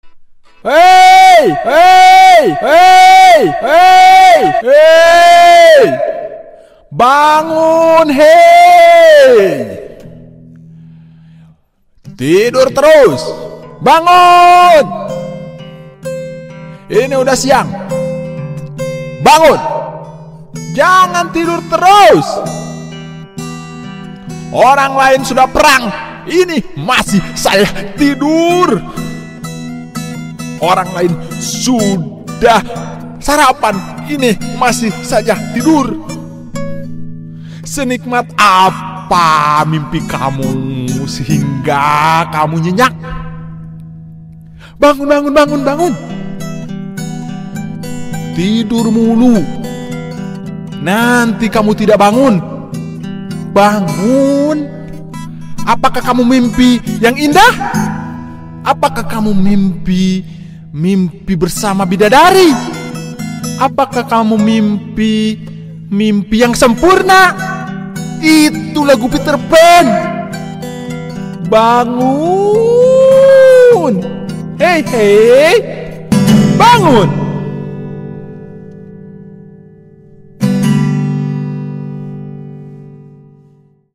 alarm woi bangun tidur pecah [download]
bunyi-alarm-bangun-tidur-100-bikin-pecah-id-technolati_com.mp3